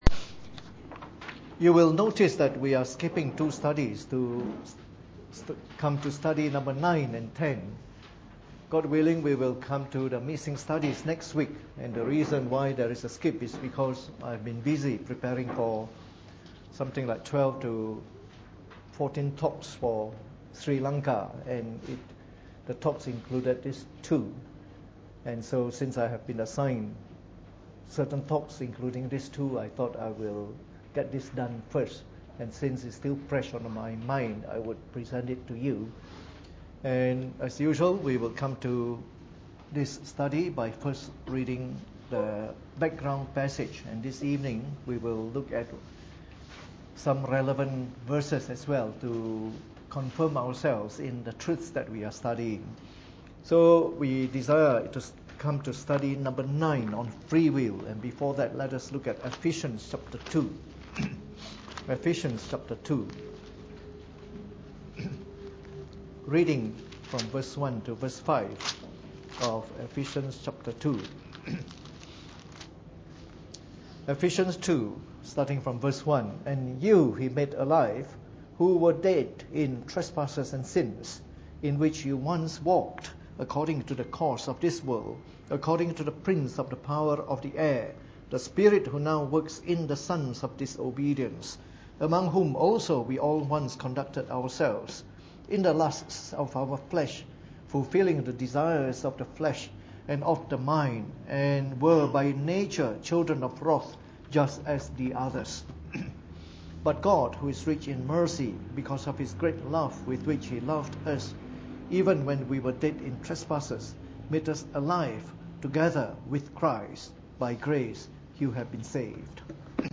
Preached on the 13th of April 2016 during the Bible Study, from our series on the Fundamentals of the Faith (following the 1689 Confession of Faith).